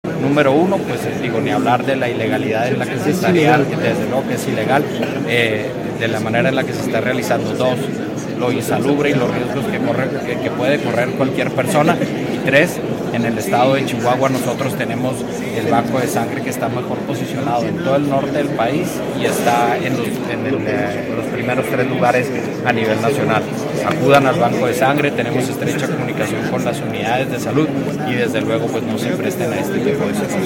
AUDIO: GILBERTO BAEZA MENDOZA, SECRETARIO DE SALUD ESTATAL (SS) 1